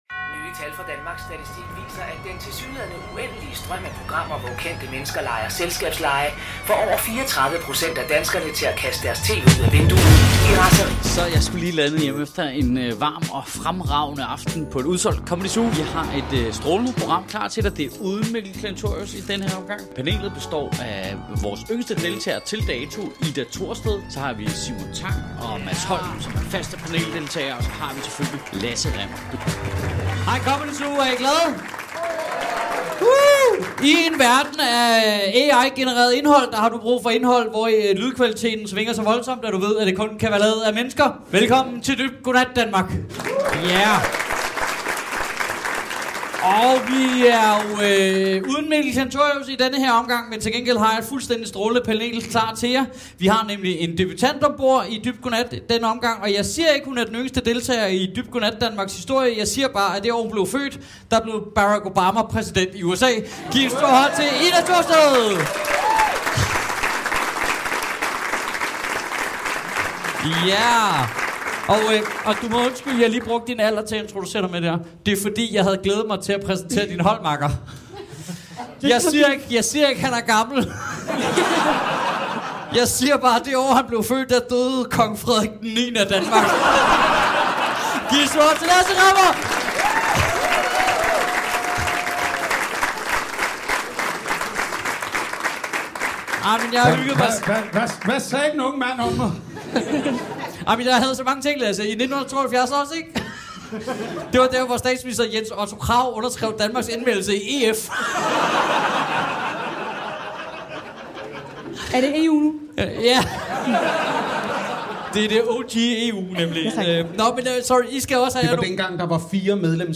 Dybt Go Nat Danmark – Dit nye favorit nyheds-panelshow 🎤